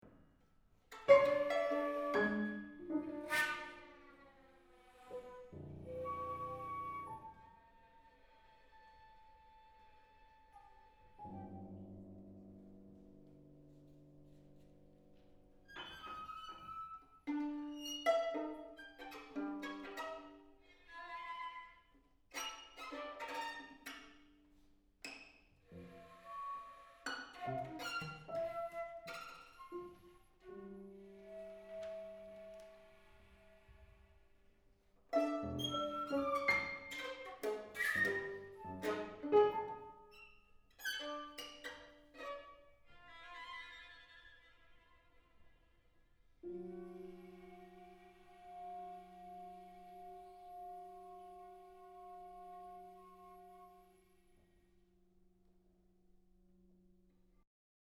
for flute/violin/piano trio (2011) [世界初演 / world première]
場所：杉並公会堂 小ホール